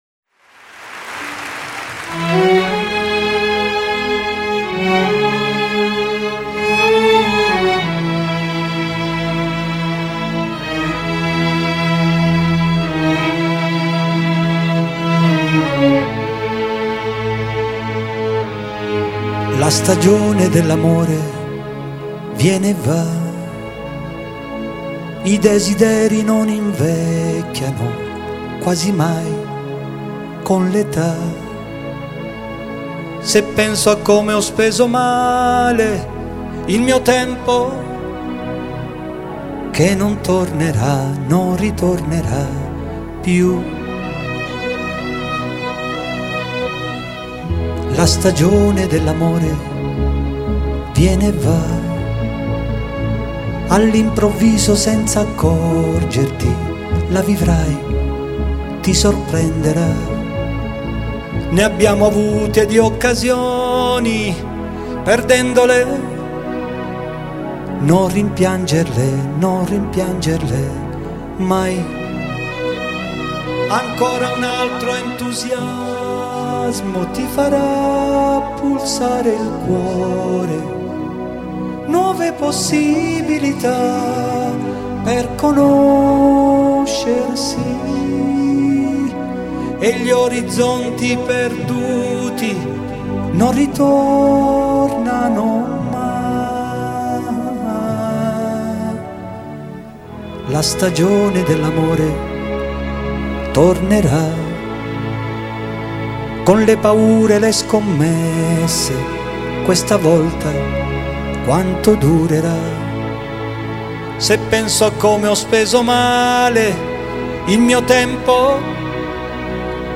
(Live)